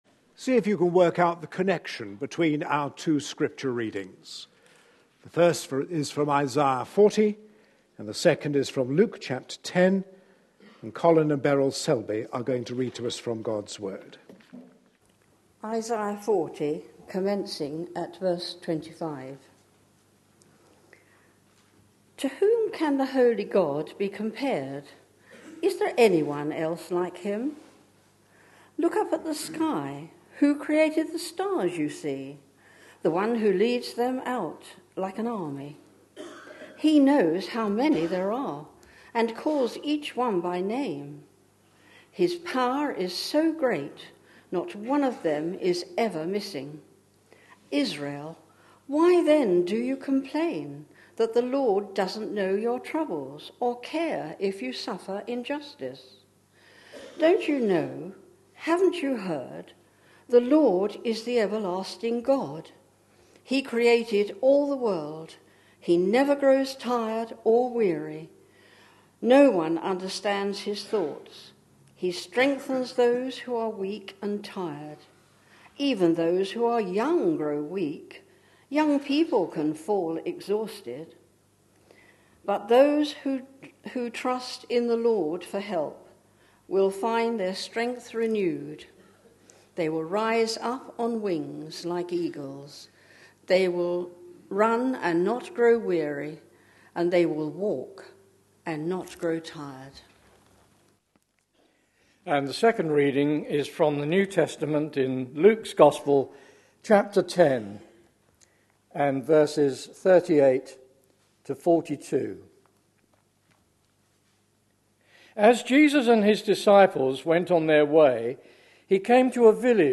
A sermon preached on 5th January, 2014, as part of our Jesus -- His Challenge To Us Today. series.